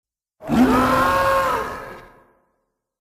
Play, download and share Riesenspinne Schrei original sound button!!!!
riesenspinne-schrei.mp3